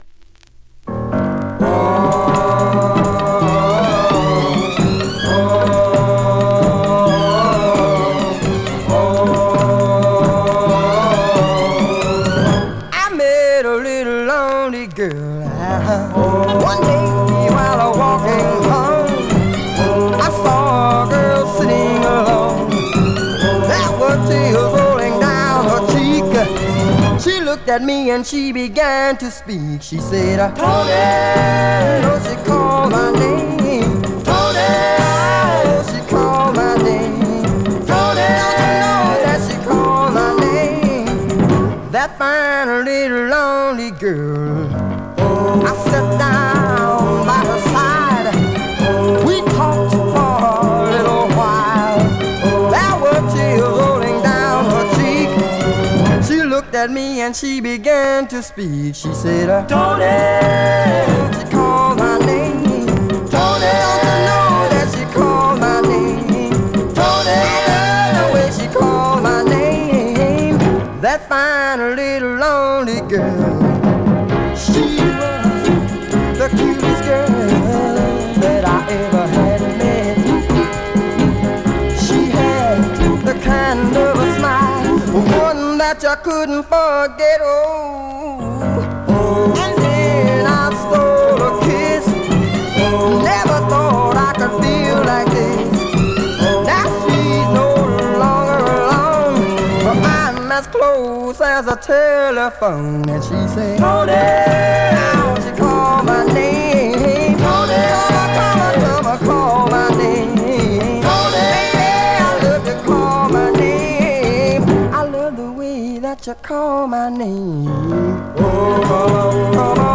Great mid-tempo Rnb / Mod dancer always popular
R&B, MOD, POPCORN